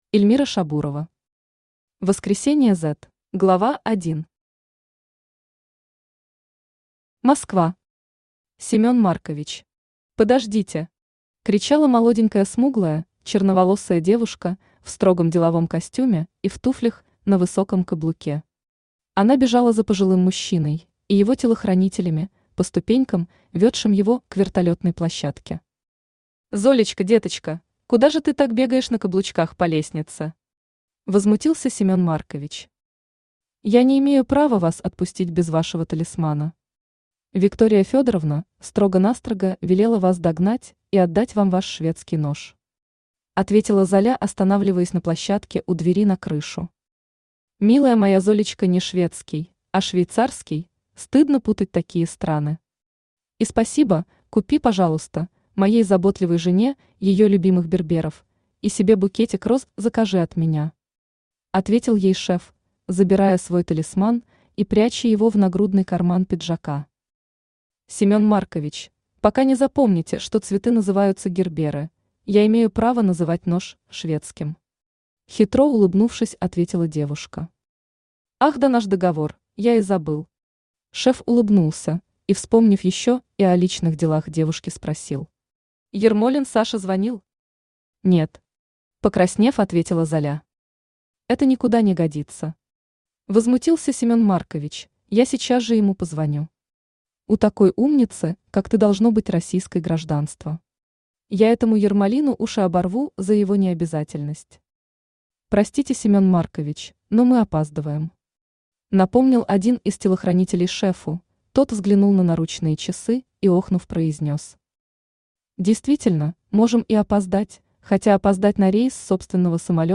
Аудиокнига Воскресение "Z" | Библиотека аудиокниг
Aудиокнига Воскресение "Z" Автор Эльмира Шабурова Читает аудиокнигу Авточтец ЛитРес.